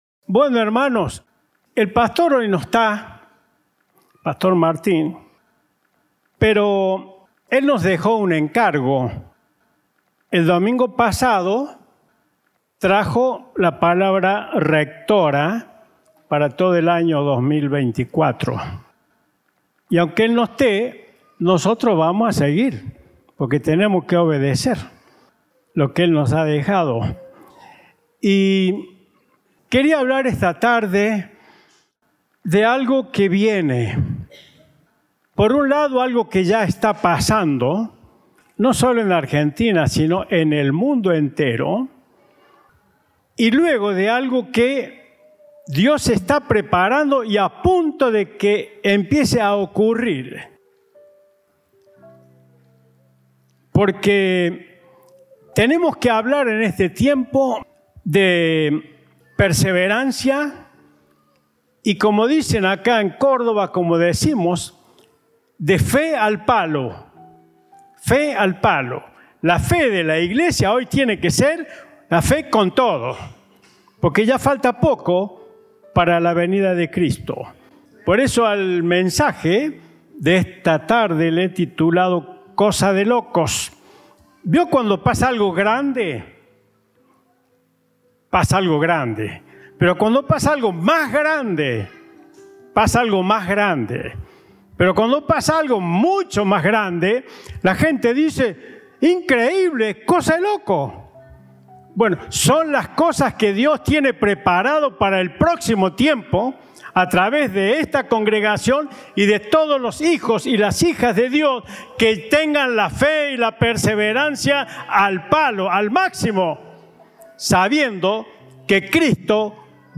Compartimos el mensaje del Domingo 14 de Enero de 2024